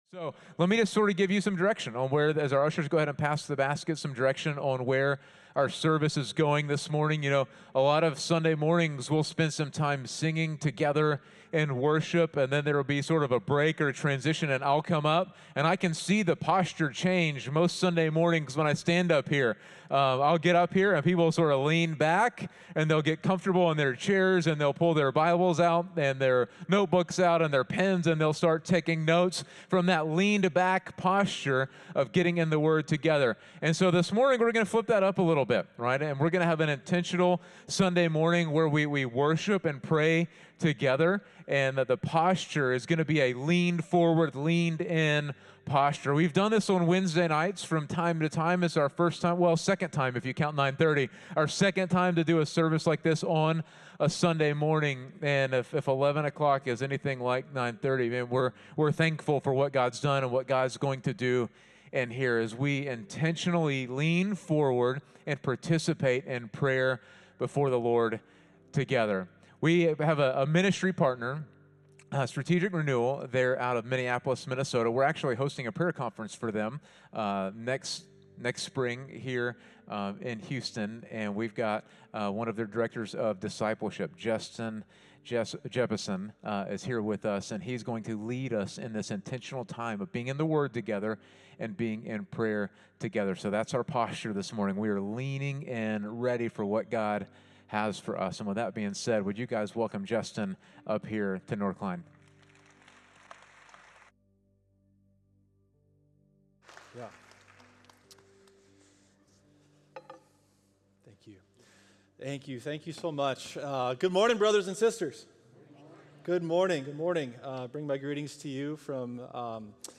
North Klein Sermons – Media Player